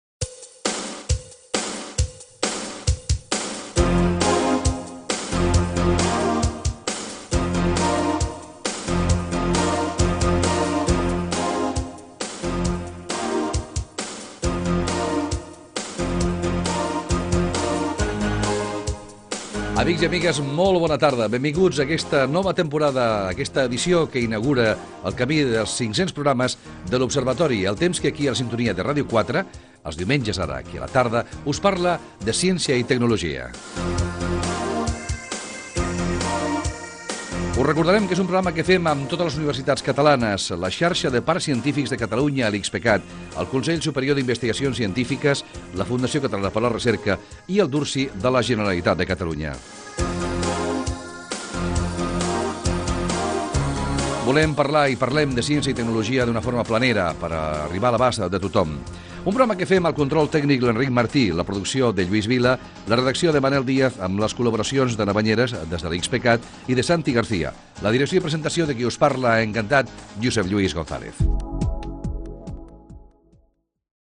Presentació del programa, en l'inici d'una nova temporada, entitats que hi col·laboren, objectiu del programa i equip.
Gènere radiofònic
Divulgació